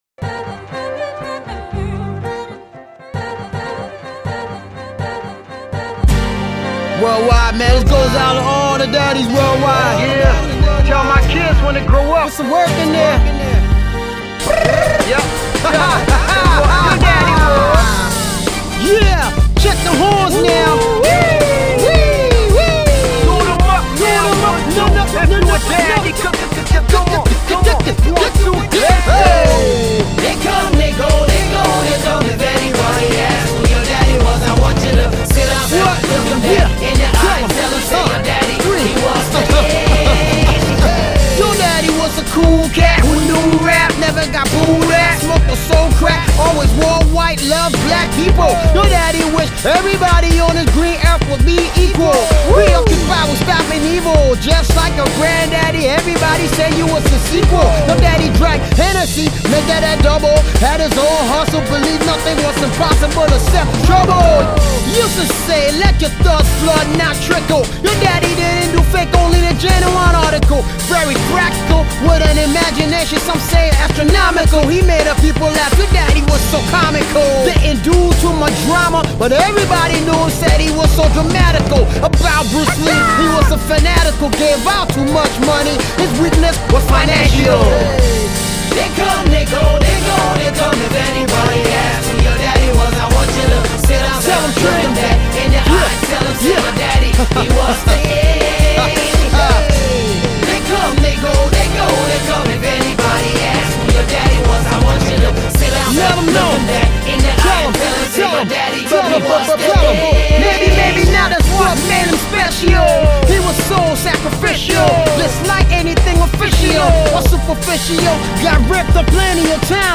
Ghanaian Hip-life Legend
versatile Ghanaian Rapper and Singer